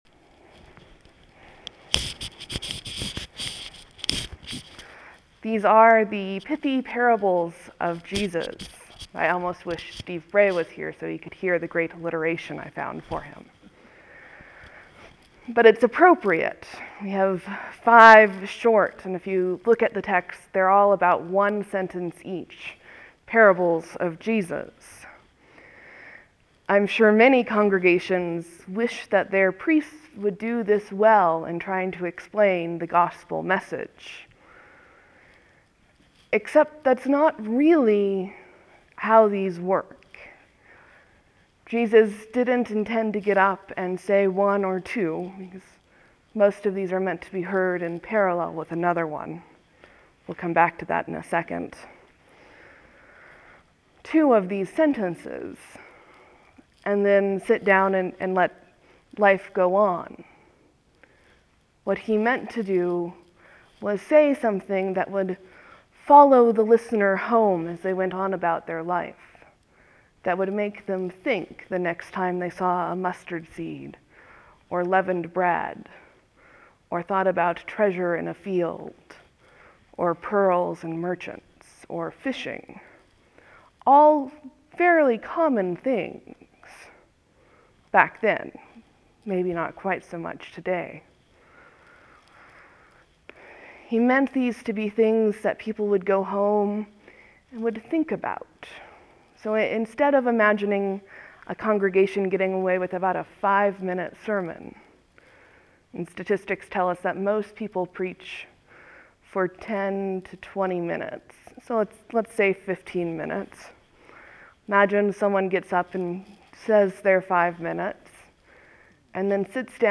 (There will be a few seconds of silence before the sermon starts. Thank you for your patience.)